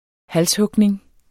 Udtale [ -ˌhɔgneŋ ]